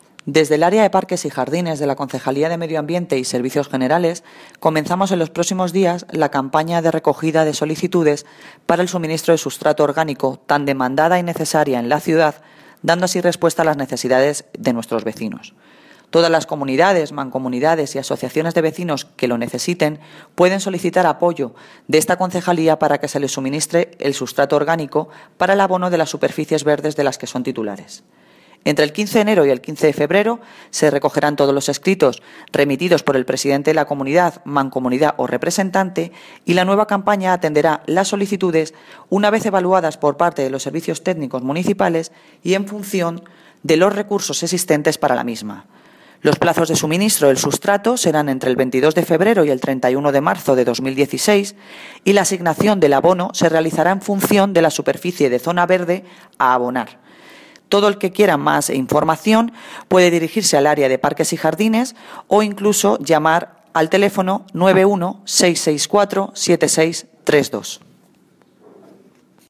Audio - Noelia Posse (Concelala de Medio Ambiente y Servicios Generales) Campaña de abono